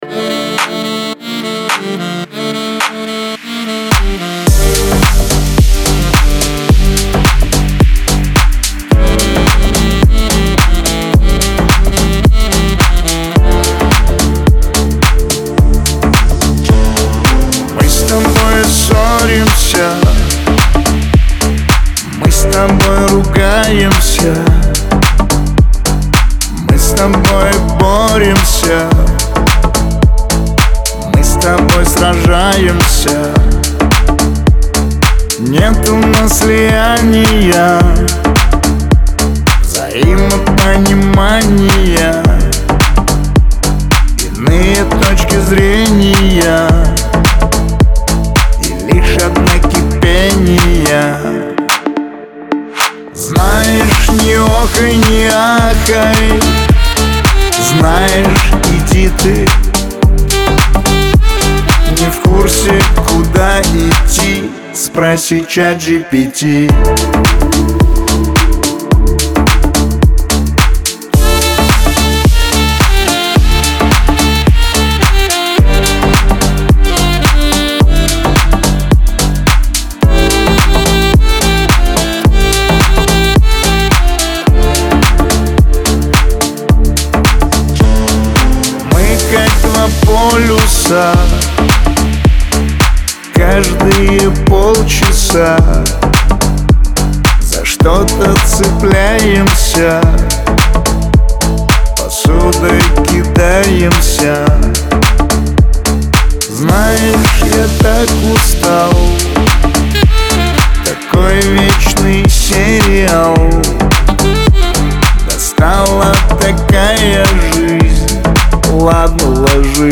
эстрада , диско
pop